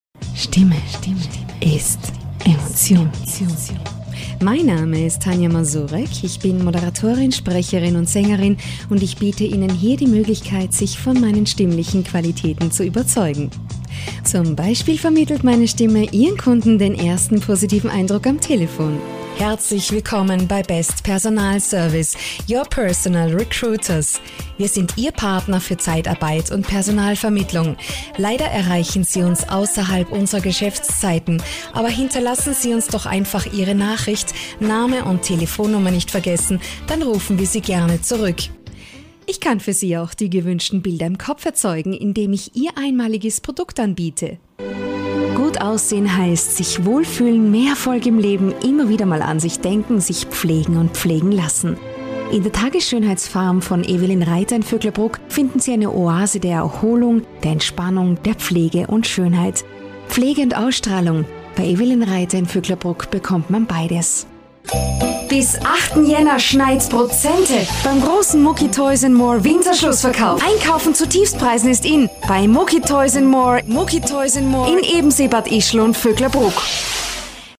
Stimmbeschreibung: Warm, einfühlsam, erotisch/sexy, verführerisch, werblich, jung oder reif, elegant, sympatisch, souverän, geheimnissvoll, sachlich/kompetent, erzählerisch, selbstbewusst und wandlungsfähig.
Sprecherin und Sängerin. Stimme: Warm, einfühlsam, erotisch / sexy, verführerisch, jung oder reif, elegant, sympatisch, souverän, geheimnissvoll,
Sprechprobe: Werbung (Muttersprache):